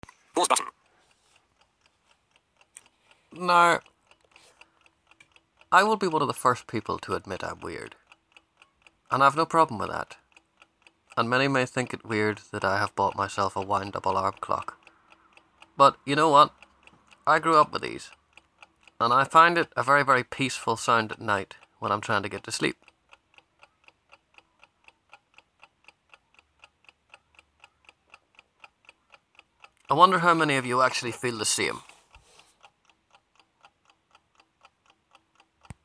Wind up clock